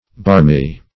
Barmy \Barm"y\ (b[aum]rm"[y^]), a.